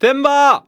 Convert all stereo sounds to mono
timber_01.ogg